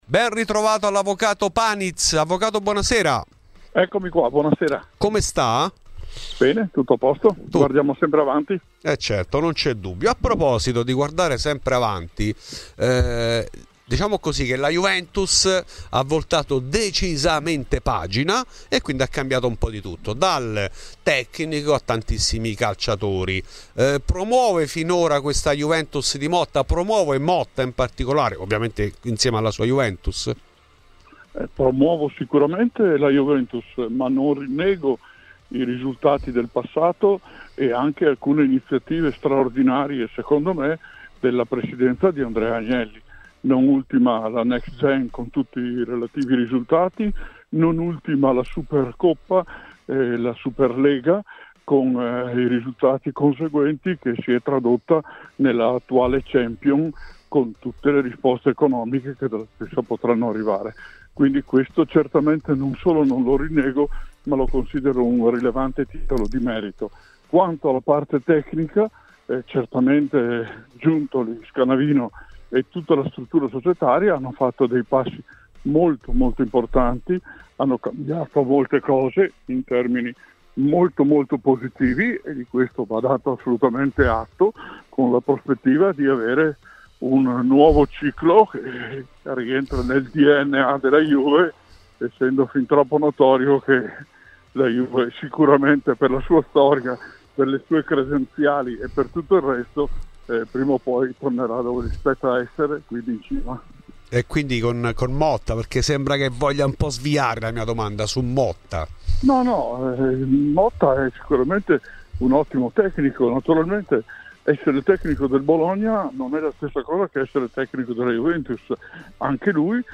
In ESCLUSIVA a Fuori di Juve l'avvocato Maurizio Paniz , presidente dello Juventus Club Parlamento.